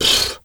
zebra_breath_02.wav